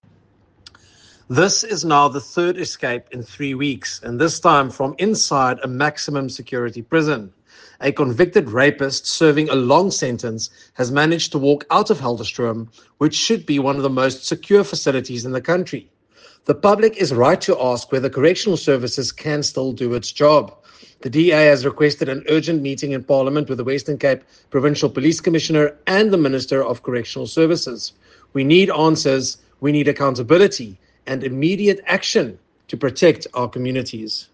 Afrikaans soundbites by Nicholas Gotsell MP.